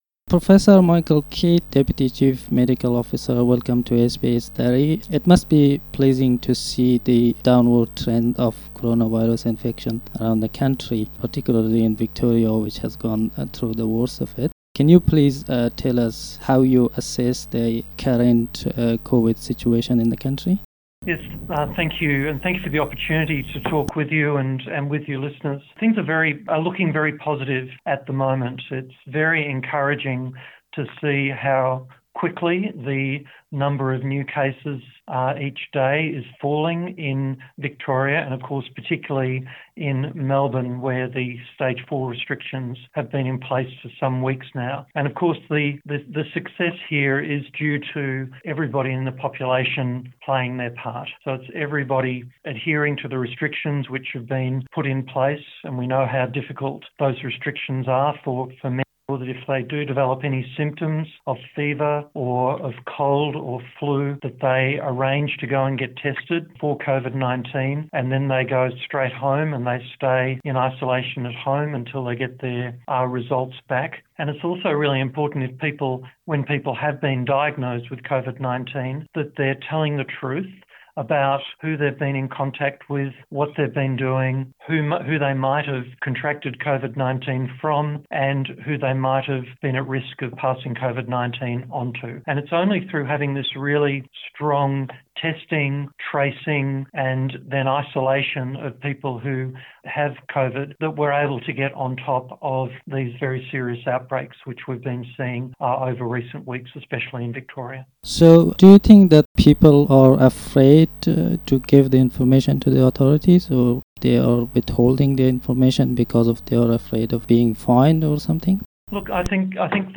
'واقعیت را بگویید و از آزمایش دادن نترسید': گفت‌وگوی اختصاصی با معاون مدیر ارشد صحی آسترالیا
dari-_interview_with_deputy_cho_michael_kidd-2.mp3